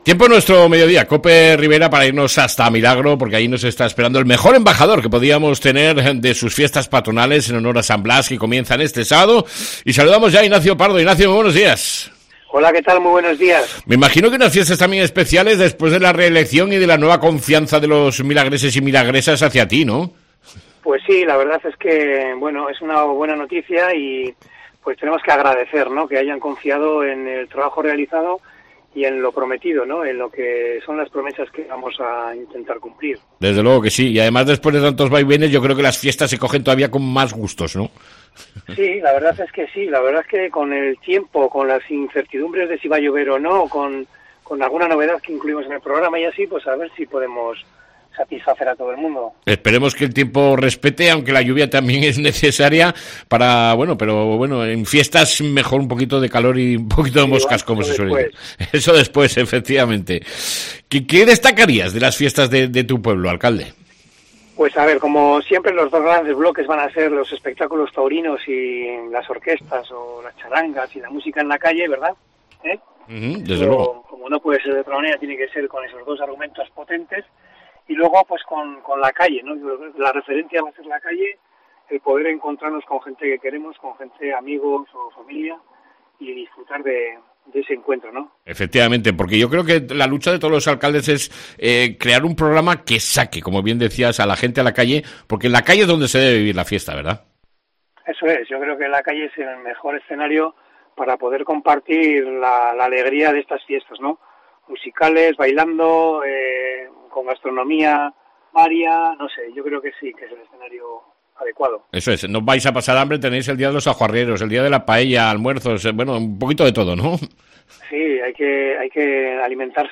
ENTREVISTA CON EL ALCALDE DE MILARO, IGNACIO PARDO